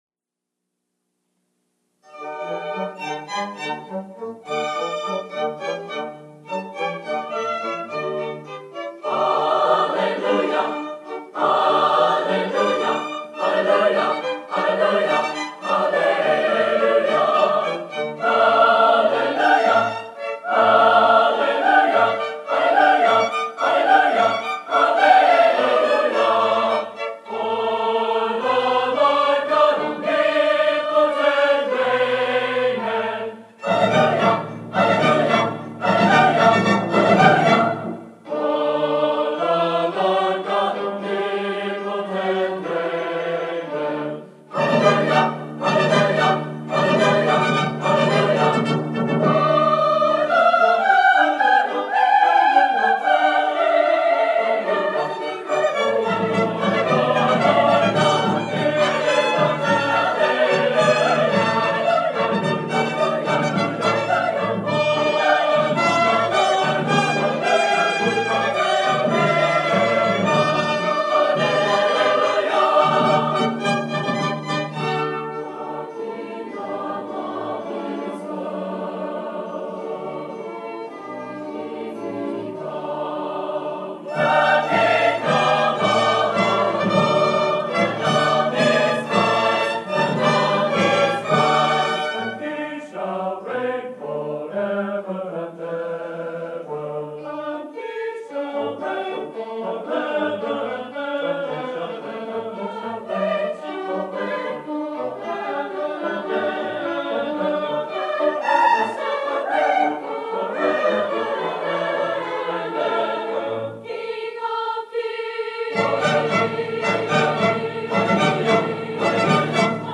Bethany Nazarene College Choral Society & Orchestra present Handel's Messiah. Performed and recorded in Herrick Auditorium on the campus of Bethany Nazarene College on 07 December 1981 at 8:00PM.